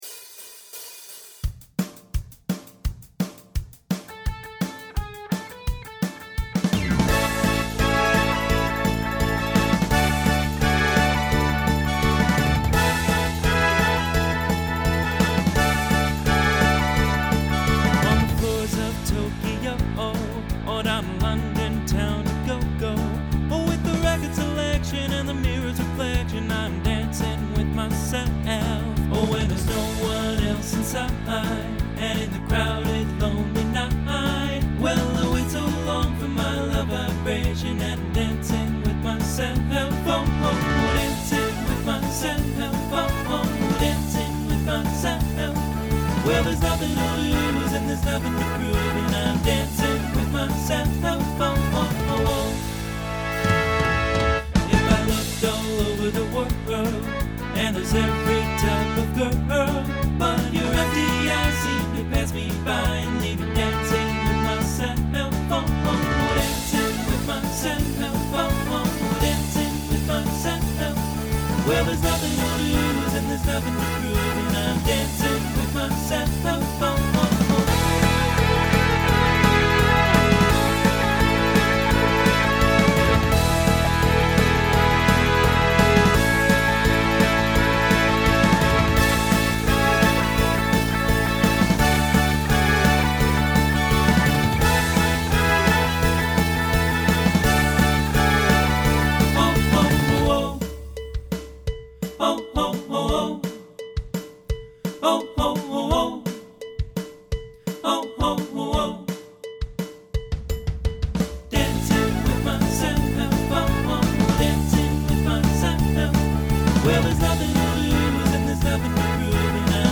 Genre Rock Instrumental combo
Voicing TTB